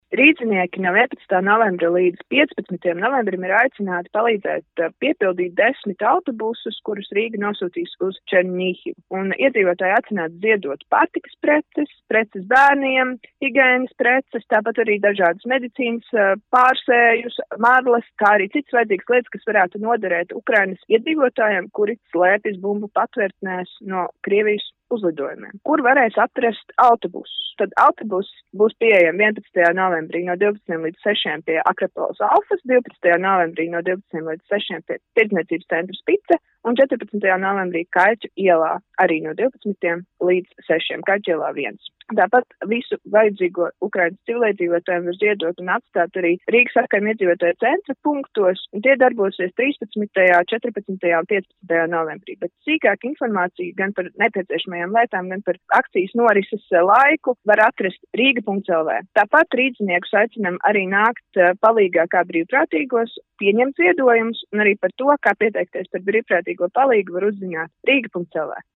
RADIO SKONTO Ziņās par Ukrainai ziedoto autobusu piepildīšanu